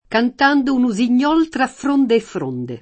tra [tra+] prep. — preferita a fra davanti a f‑ e soprattutto a fr- per ragioni eufoniche (non da tutti sentite), altrove in libera alternanza (salvo qualche uso partic.: cfr. fra): Cantando un usignol tra fronde e fronde [